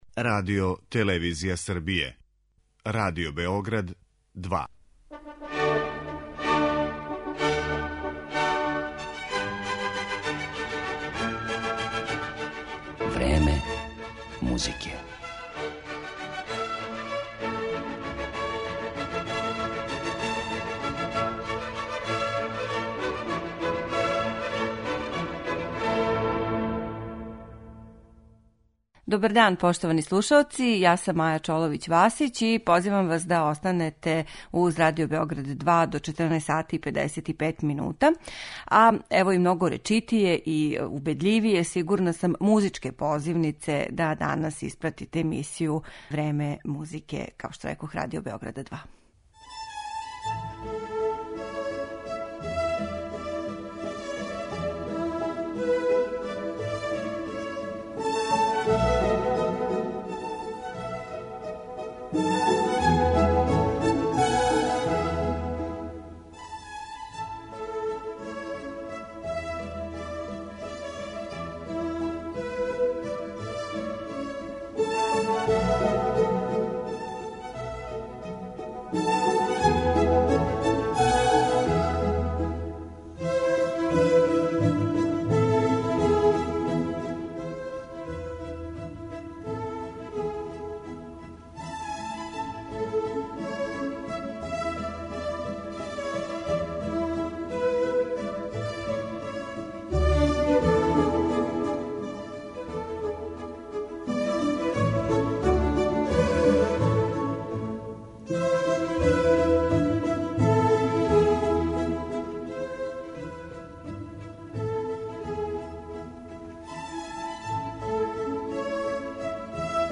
Емисију у којој ћете бити у прилици да чујете фрагменте из Бокеринијевих познатих дела